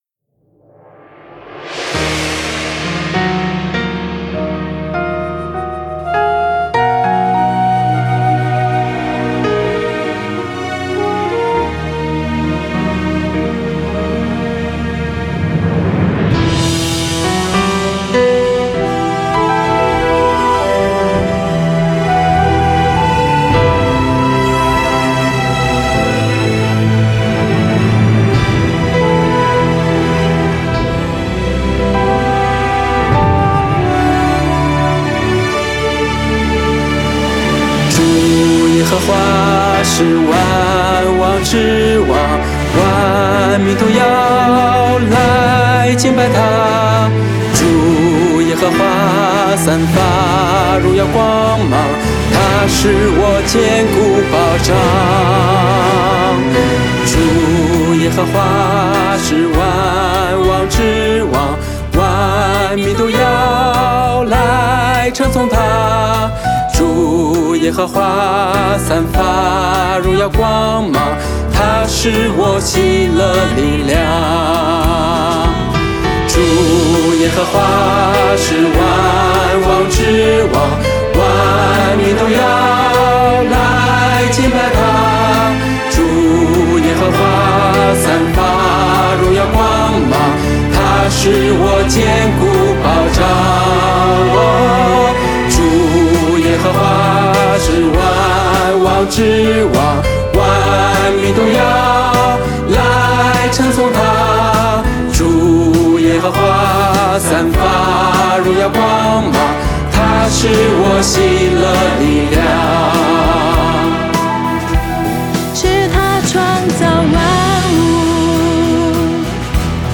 mp3 原唱音樂